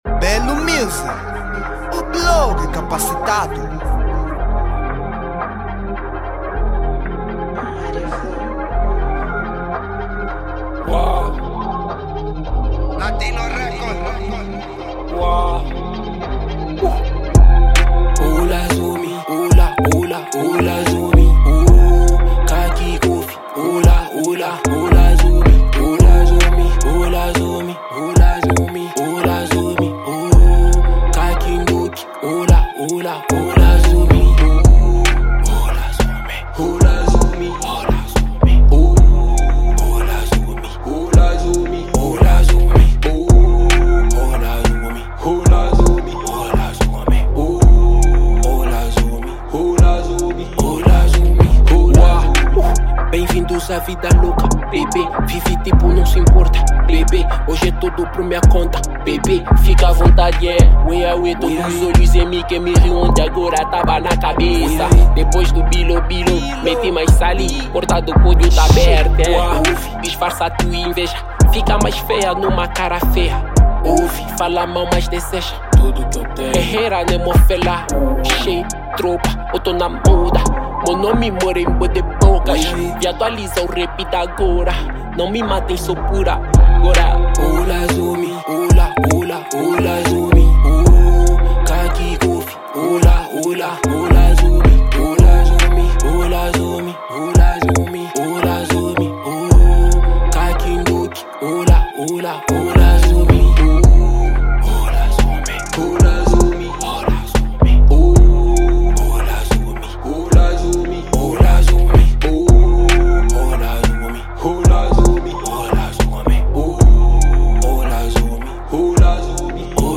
Género : Rap